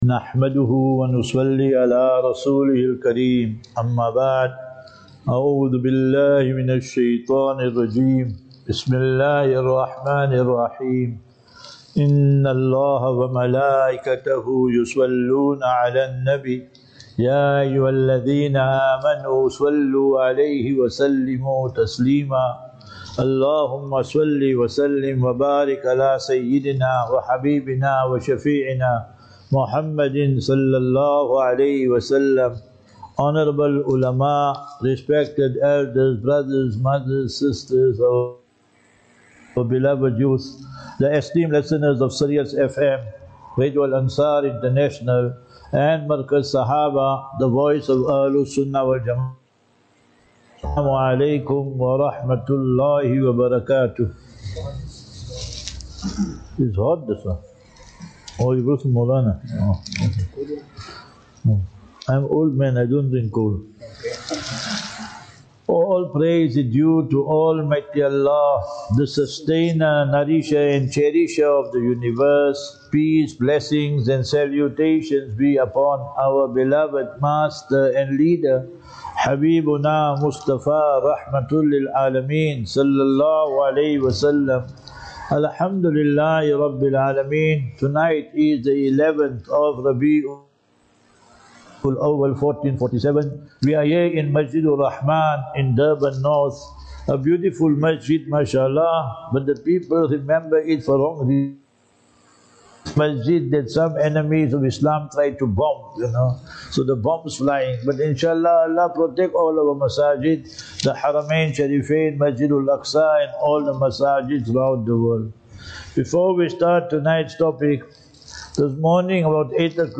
3 Sep 03 September 2025 - KZN Lecture Series
Lectures